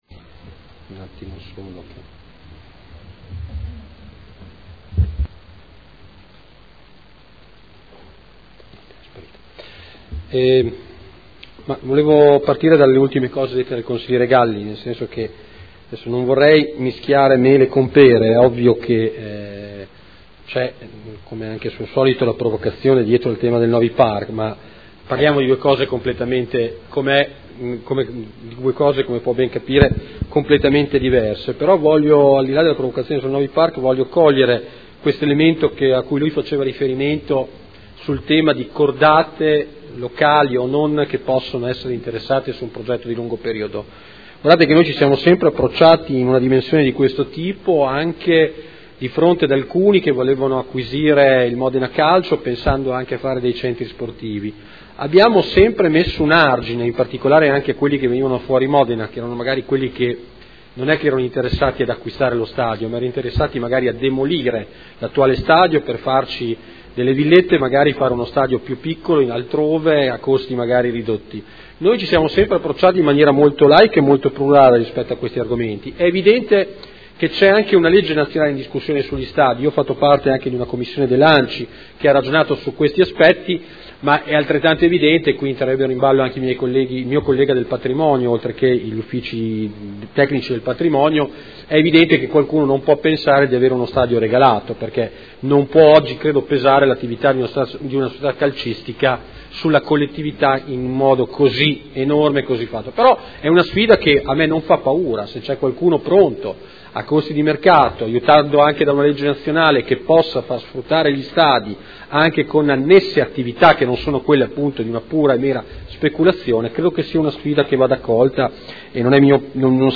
Seduta del 7 aprile. Proposta di deliberazione: Convenzione per la gestione dello stadio comunale Alberto Braglia al Modena FC SpA – Prolungamento durata. Dibattito